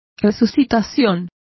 Complete with pronunciation of the translation of revivals.